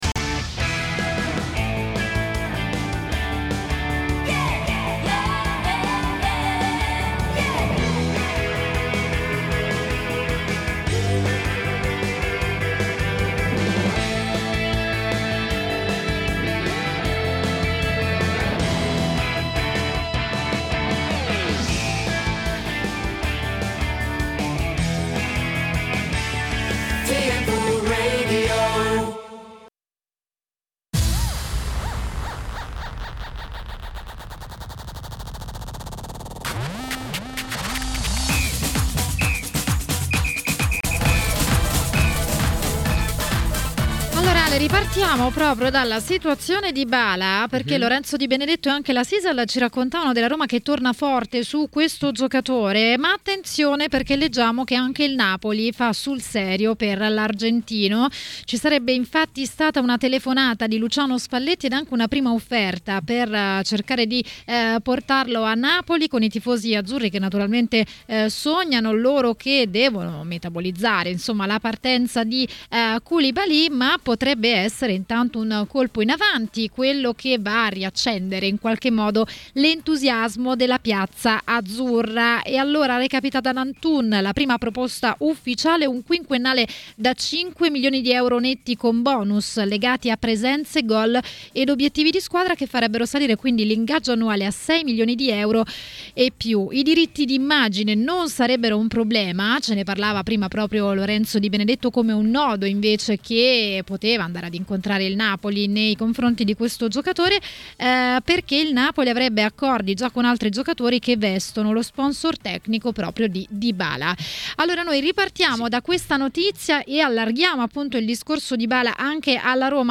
è intervenuto a TMW Radio, durante Maracanà.